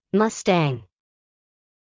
「Mustang」の英語の発音記号は「mʌ́stæŋ」ですので、英語の発音に近づけるのであれば「マスタング」が正しいでしょう。
↓mustangの発音
カタカナで表すなら「マスタン」が近いかもしれません。
mustang.mp3